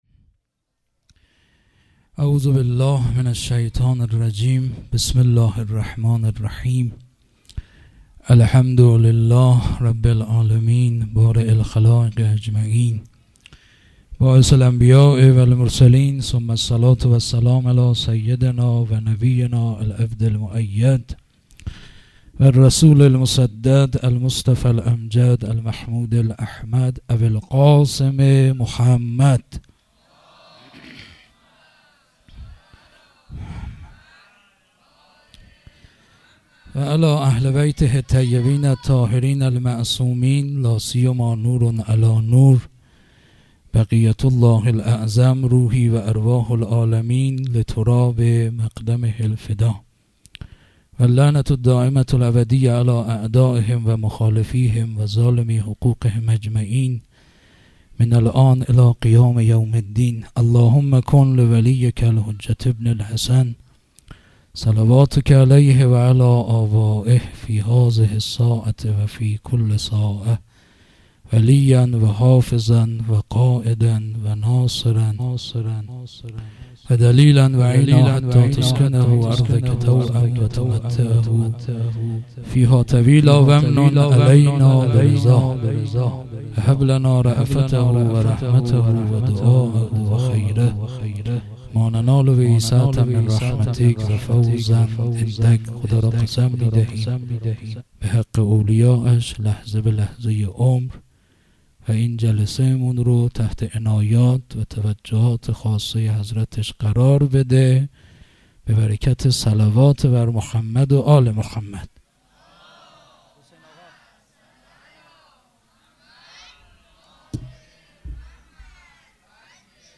شب قدر ۱۴۴۳ شب دوم